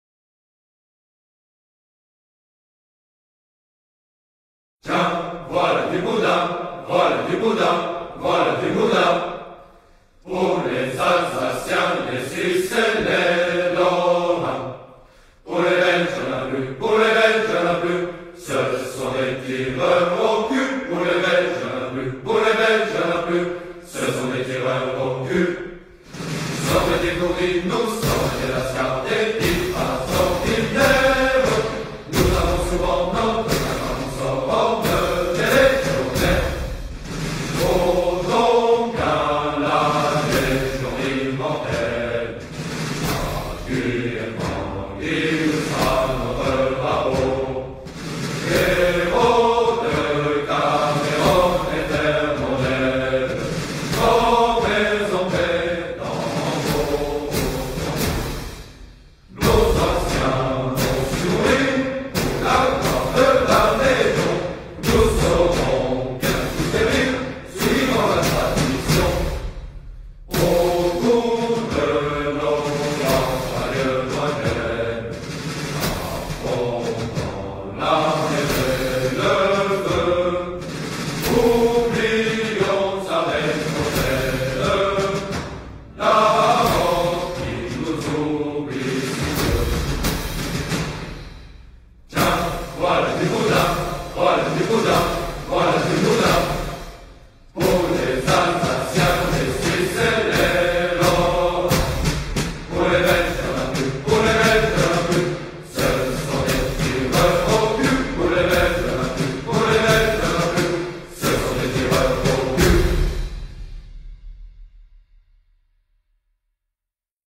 ''Le Boudin''(the sausage) - French Foreign Legion March.mp3